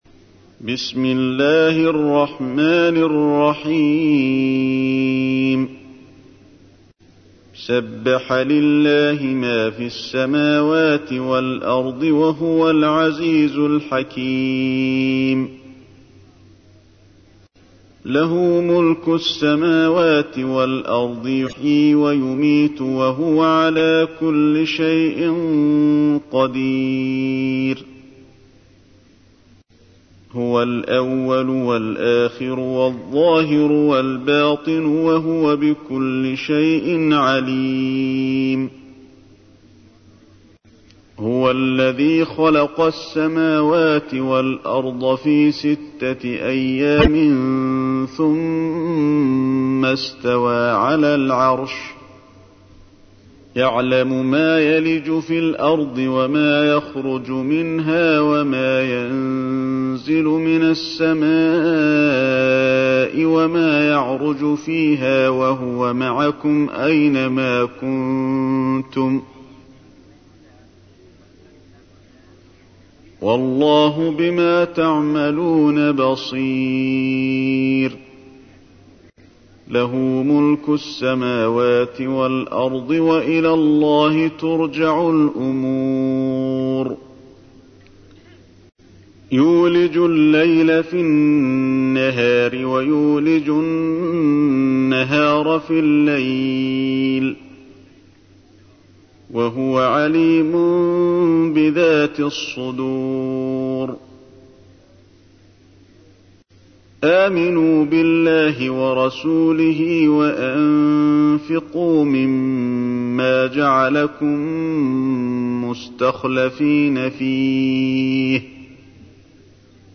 تحميل : 57. سورة الحديد / القارئ علي الحذيفي / القرآن الكريم / موقع يا حسين